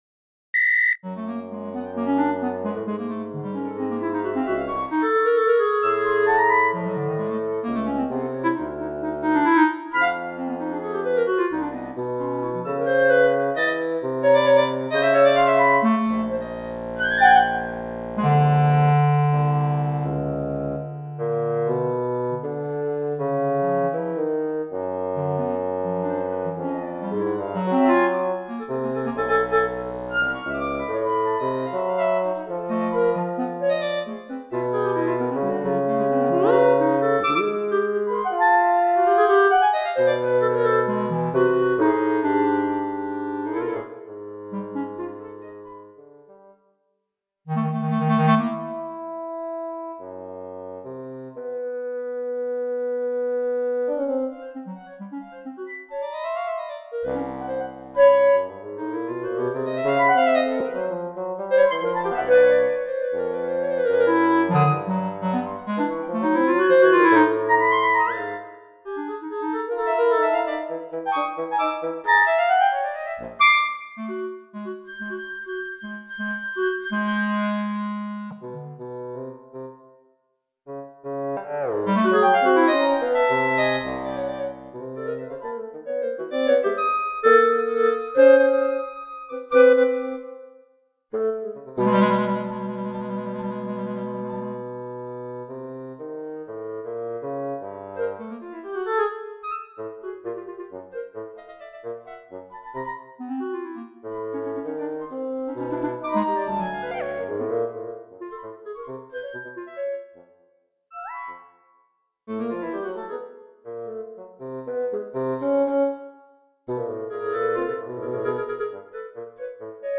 As you can hear, the clarinet has now-fragmented, now-legato line. The bass, a constant one.